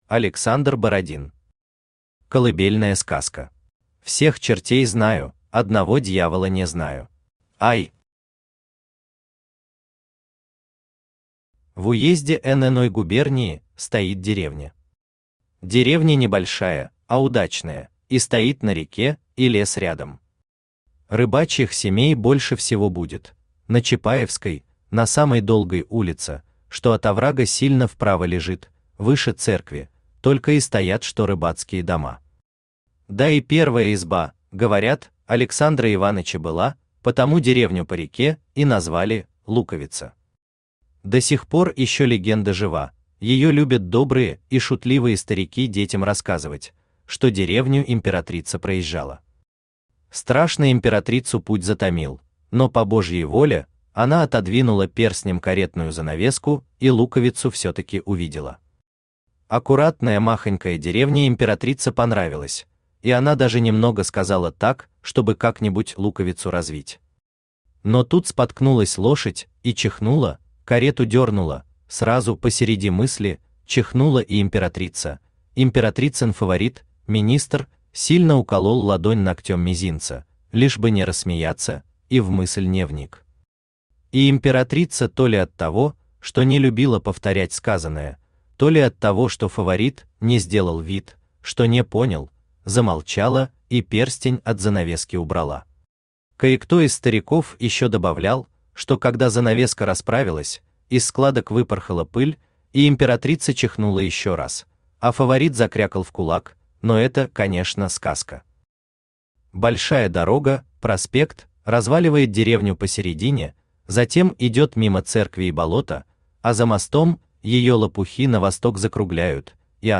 Аудиокнига Колыбельная сказка | Библиотека аудиокниг
Aудиокнига Колыбельная сказка Автор Александр Бородин Читает аудиокнигу Авточтец ЛитРес.